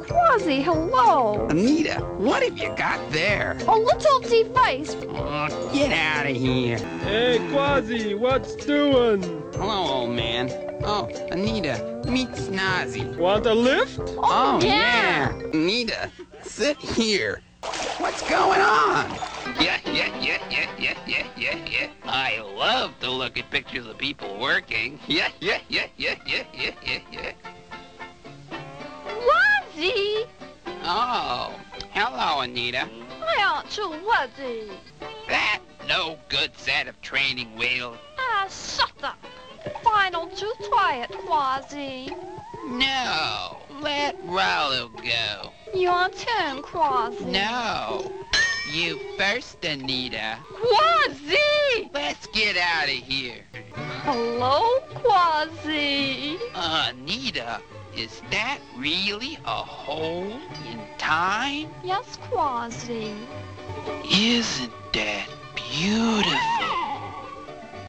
He has a grand total of like 40 seconds of voice time between Quackadero and Psychic.